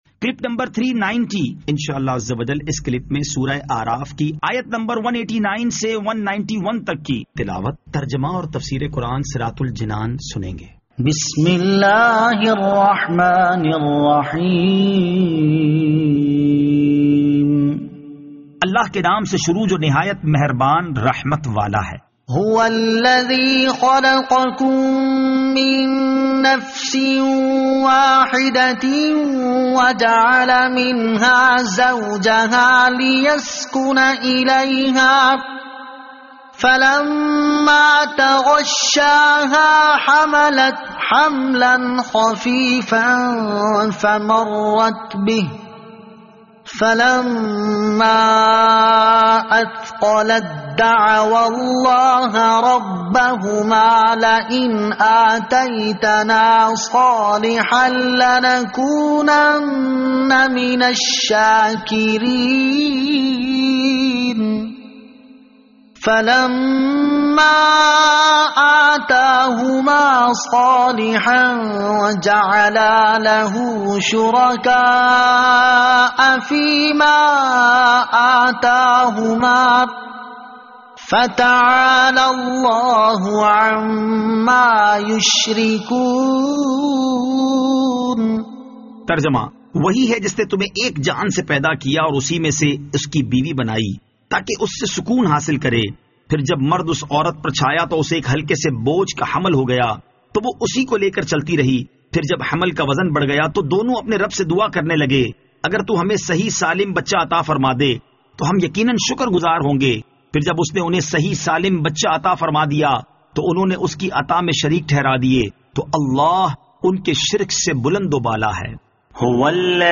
Surah Al-A'raf Ayat 189 To 191 Tilawat , Tarjama , Tafseer